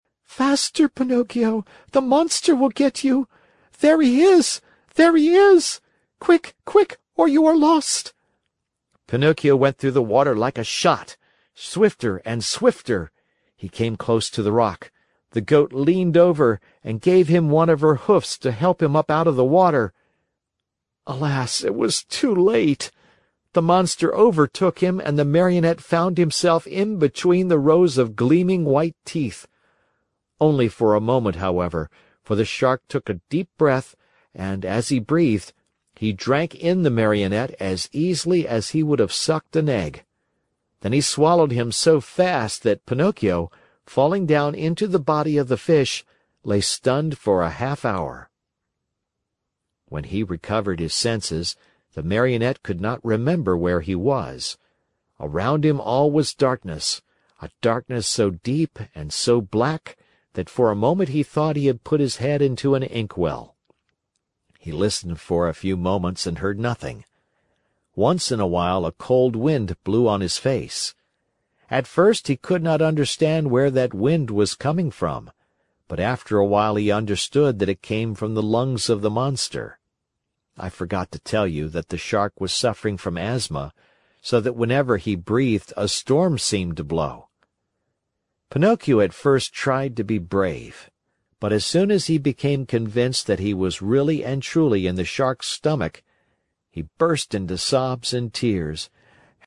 在线英语听力室木偶奇遇记 第145期:匹诺曹落入鱼腹(7)的听力文件下载,《木偶奇遇记》是双语童话故事的有声读物，包含中英字幕以及英语听力MP3,是听故事学英语的极好素材。